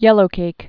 (yĕlō-kāk)